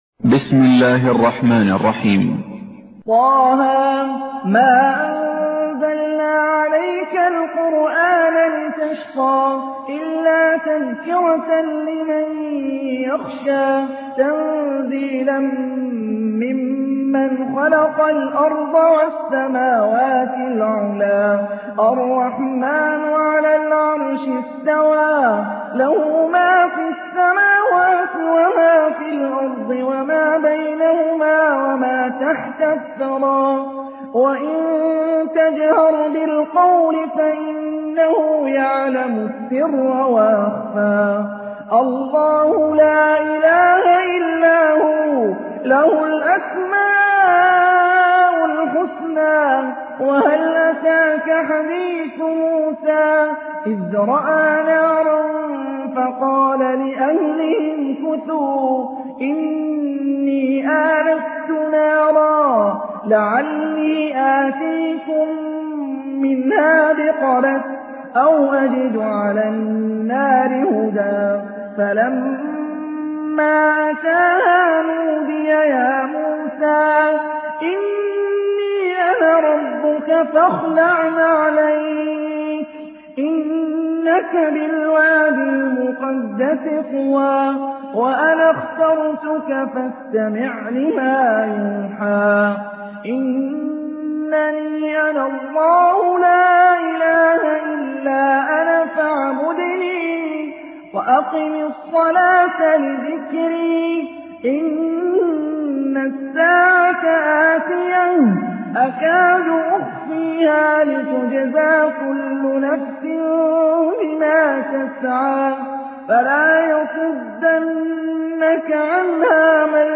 تحميل : 20. سورة طه / القارئ هاني الرفاعي / القرآن الكريم / موقع يا حسين